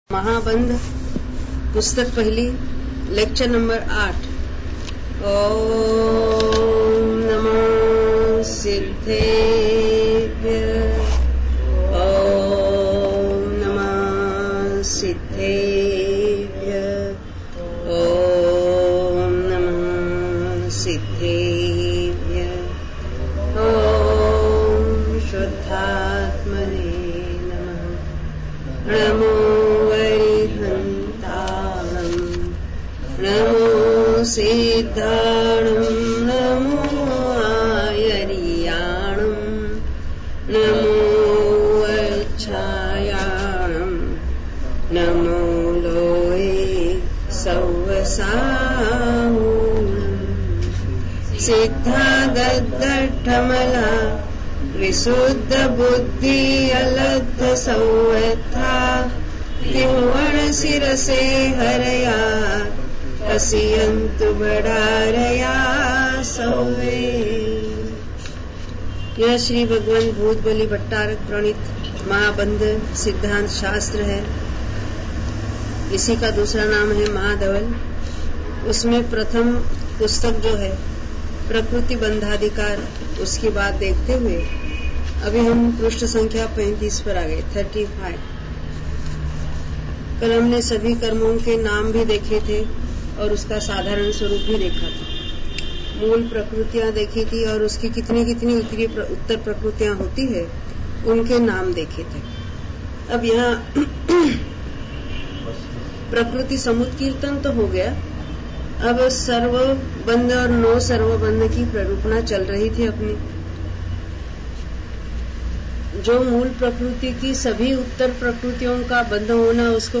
Pravachan